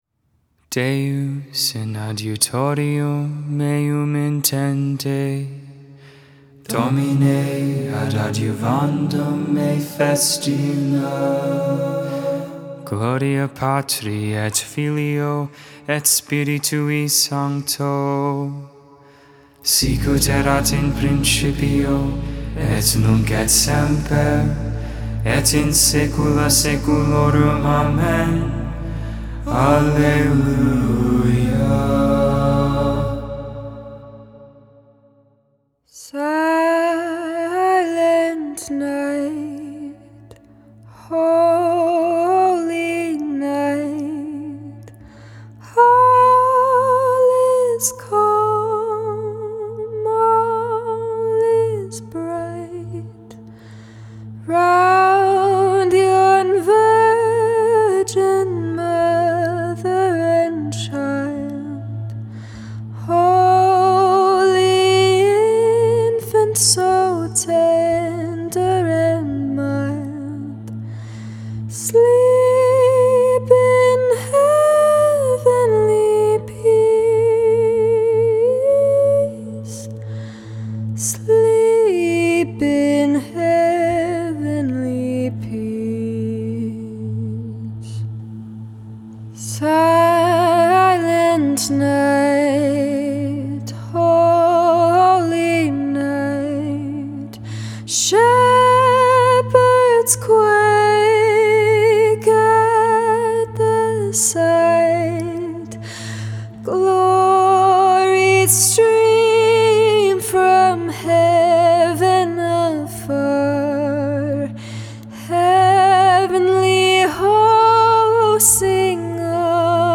Vespers II, Saturday Evening Prayer, December 25th, 2022 (for the Solemnity of the Nativity of our Lord, a.k.a. CHRISTMAS!)
Hymn